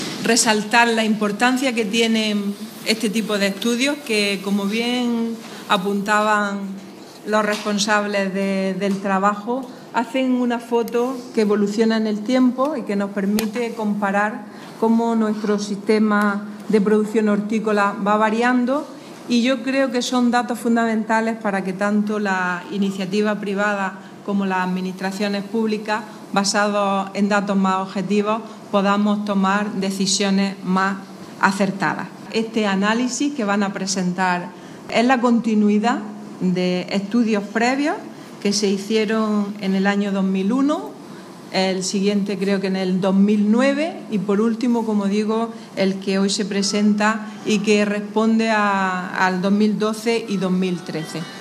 Declaraciones de Carmen Ortiz sobre Control Biológico y calidad diferenciada